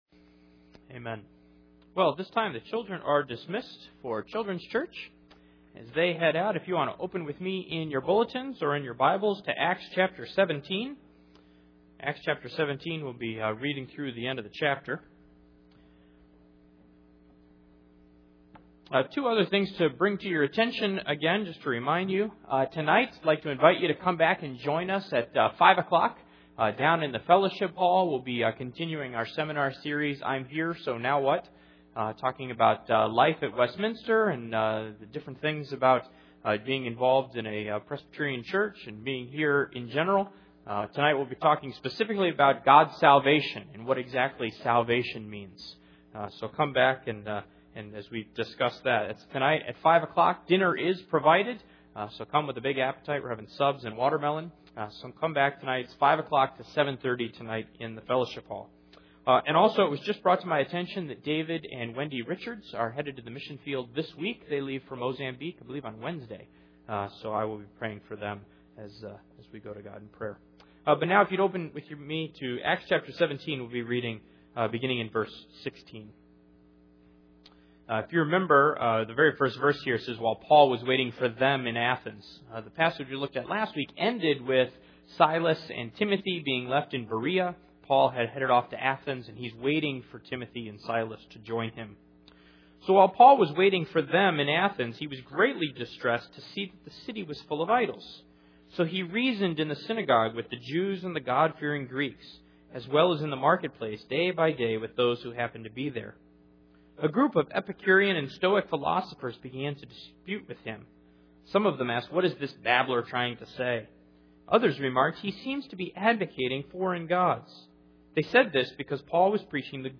Passage: Acts 17:16-34 Service Type: Sunday Morning %todo_render% « Complacency